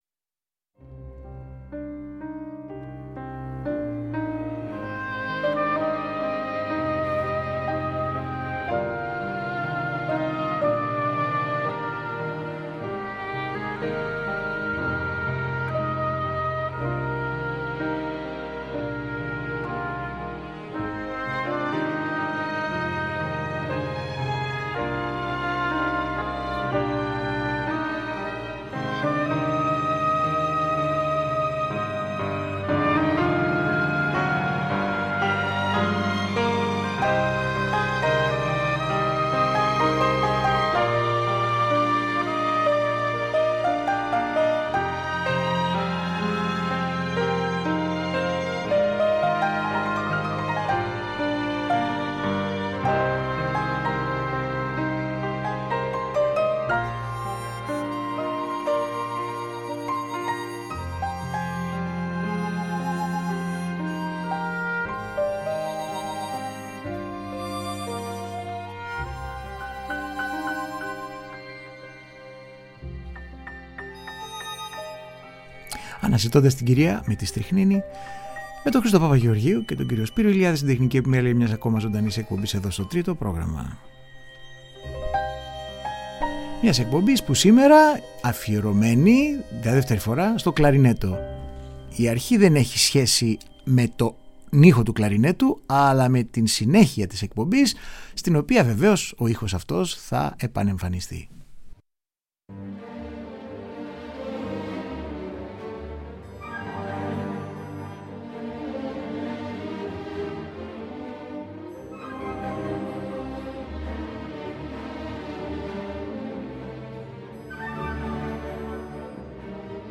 Μουσική για το κλαρινέτο σε συγκριτικές ακροάσεις – μέρος 2ο
Σπουδαίοι ερμηνευτές και μαγικές μεταγραφές έργων που αλλάζουν σημασία και αποκτούν μια νέα διάσταση μέσα από τον βελούδινο ήχο του οργάνου που αγάπησε τόσο η κλασική όσο και η τζαζ μουσική.